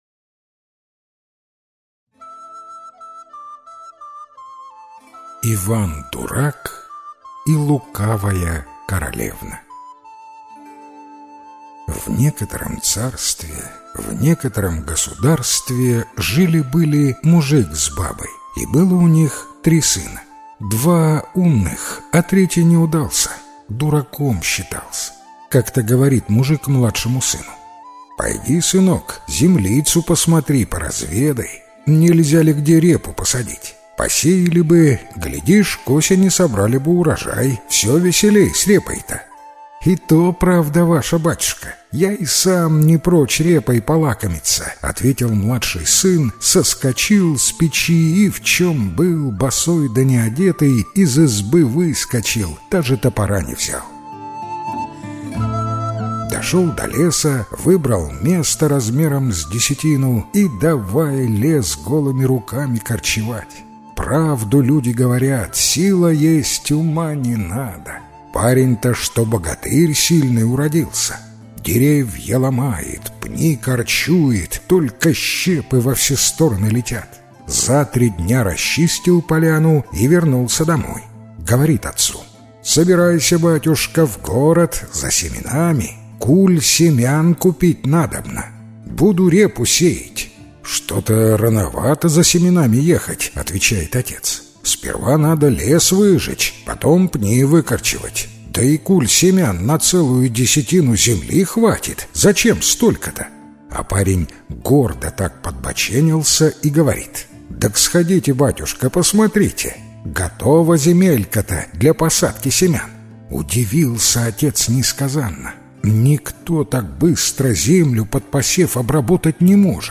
Иван-дурак и лукавая королевна - белорусская аудиосказка - слушать онлайн